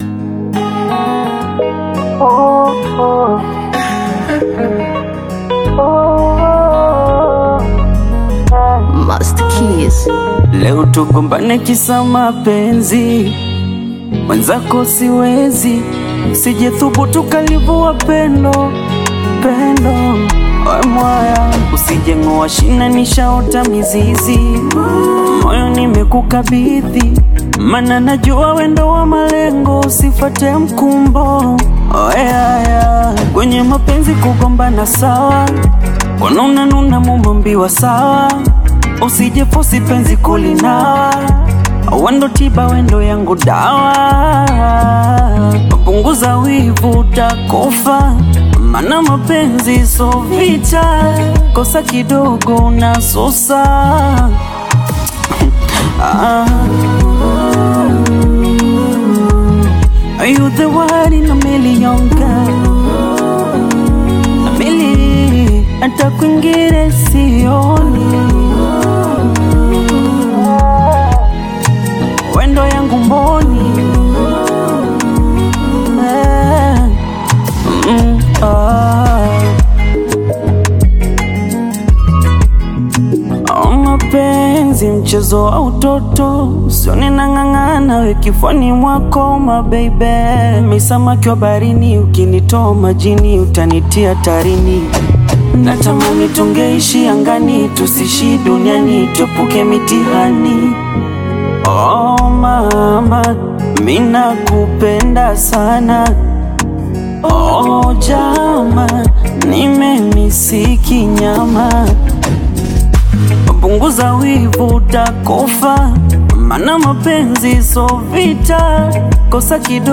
Bongo Flava
Love song